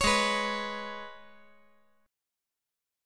Listen to Abmbb5 strummed